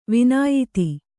♪ vināyiti